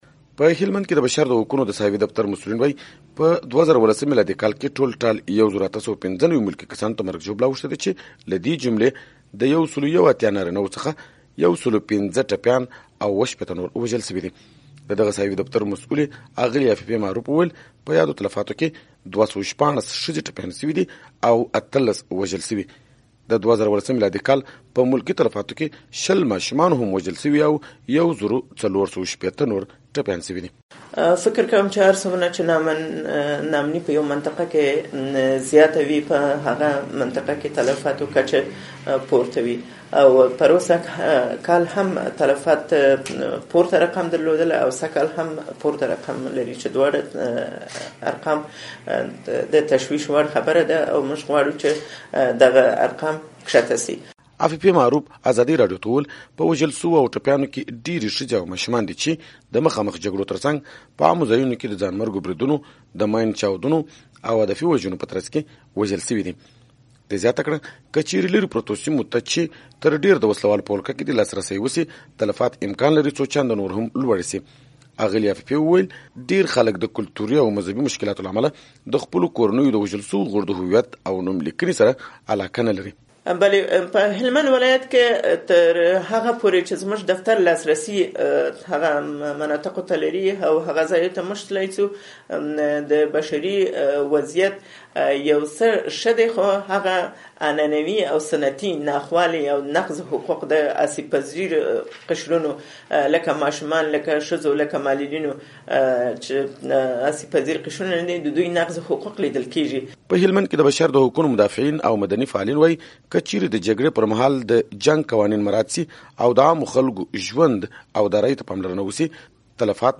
تحقیقی راپورونه